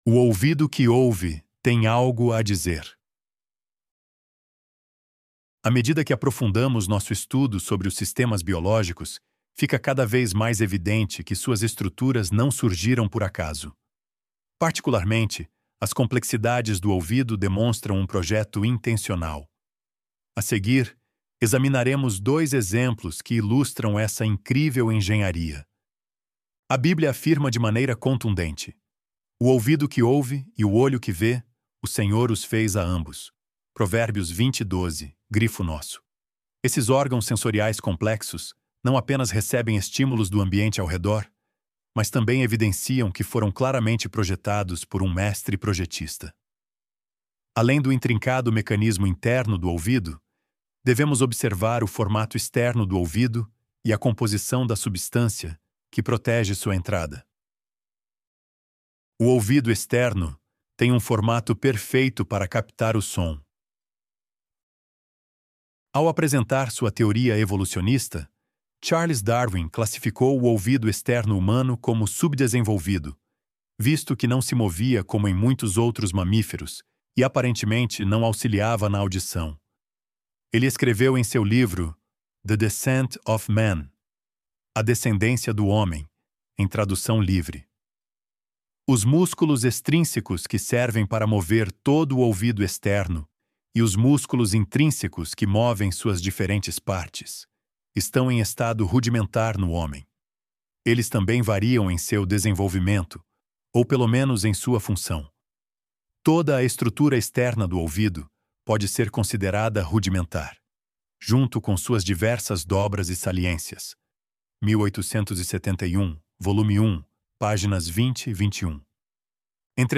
ElevenLabs_O_Ouvido_Que_Ouve_Tem_Algo_A_Dizer.mp3